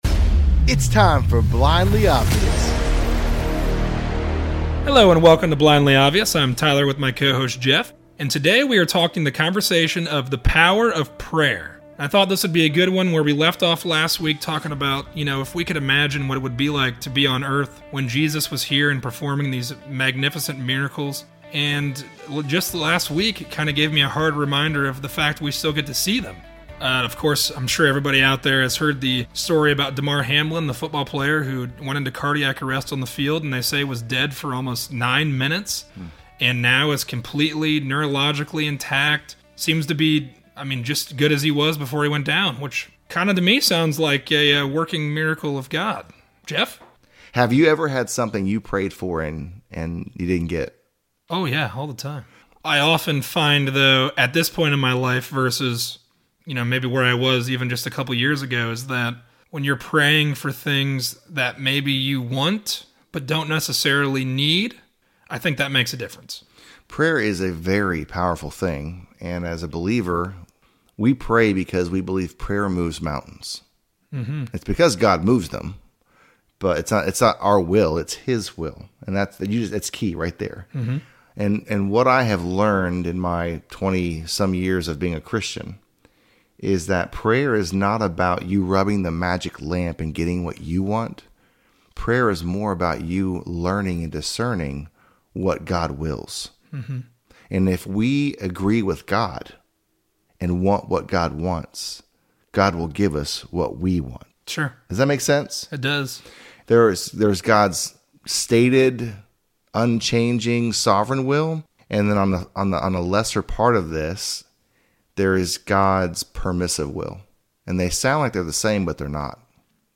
A conversation on the power of prayer. Is our God a genie who grants every last wish of our words or someone who creates exactly what we need in our lives, when our will aligns with His?